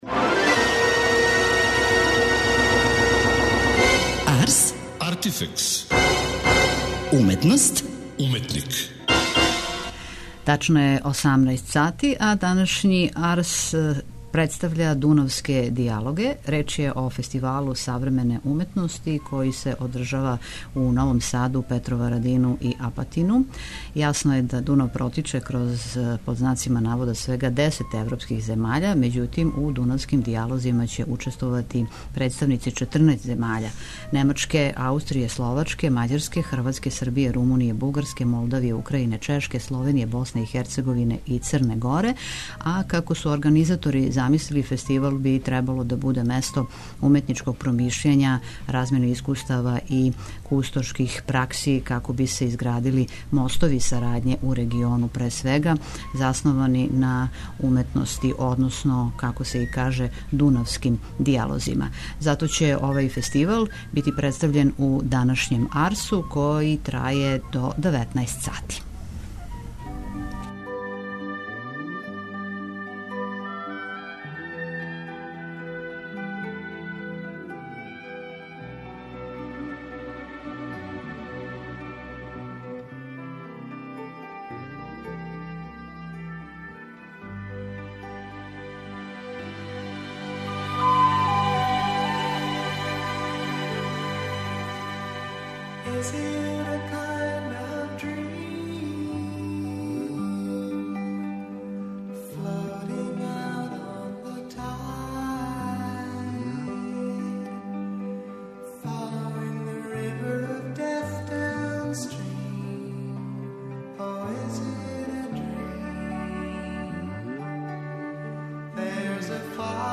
У емисији доносимо детаље и разговорамо са учесницима и организаторима.